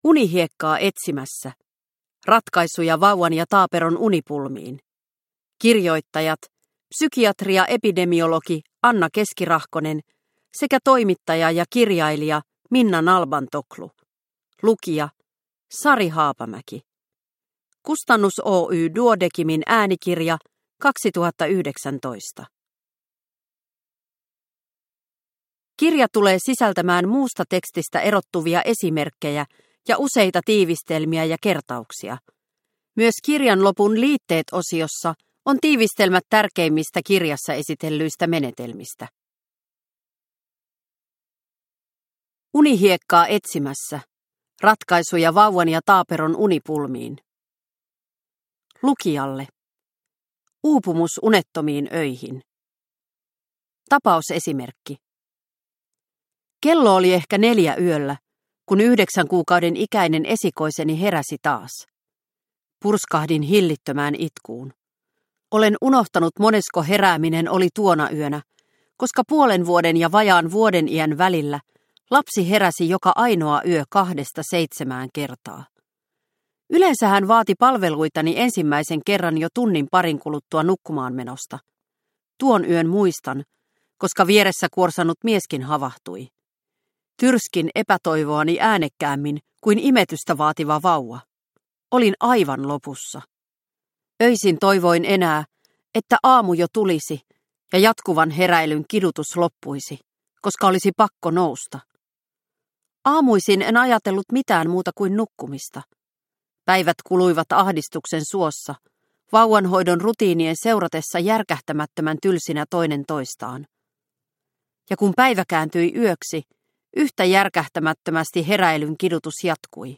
Unihiekkaa etsimässä – Ljudbok – Laddas ner